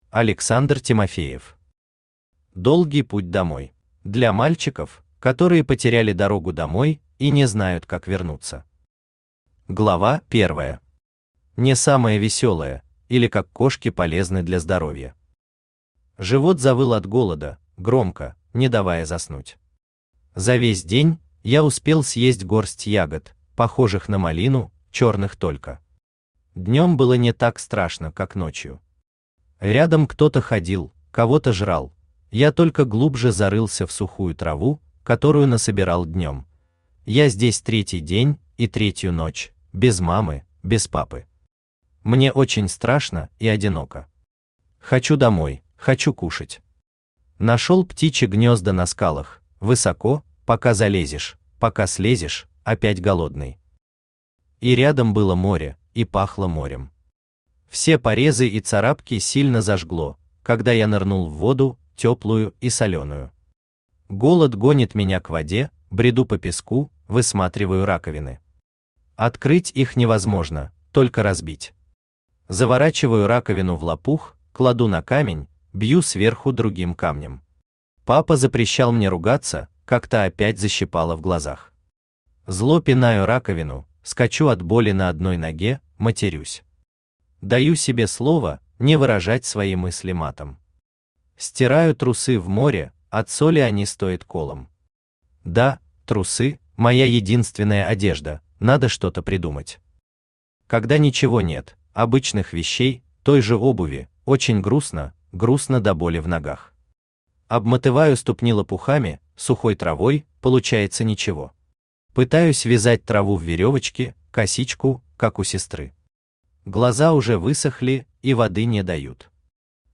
Аудиокнига Долгий путь домой | Библиотека аудиокниг
Aудиокнига Долгий путь домой Автор Александр Тимофеев Читает аудиокнигу Авточтец ЛитРес.